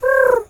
pigeon_2_call_calm_04.wav